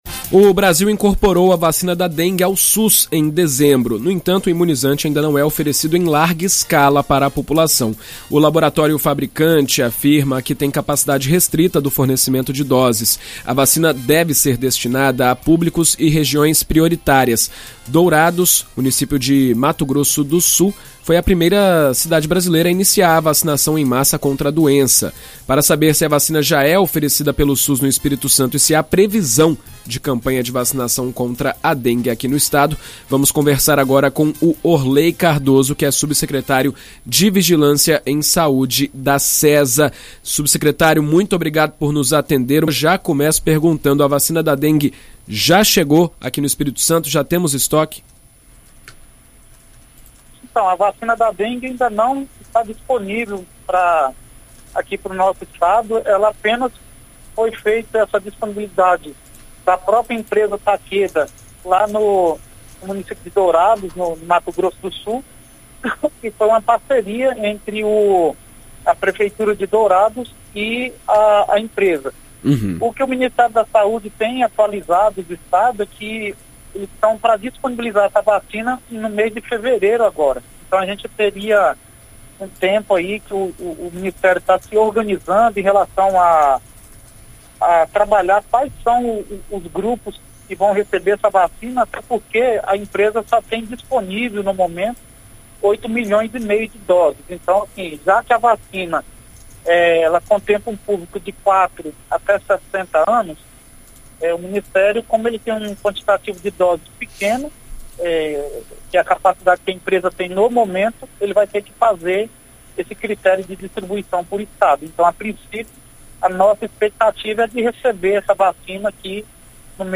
Em entrevista à BandNews FM ES nesta quinta-feira (04), o subsecretário de Vigilância em Saúde da Sesa, Orlei Cardoso, afirma que o estado ainda não recebeu doses do imunizante pelo SUS.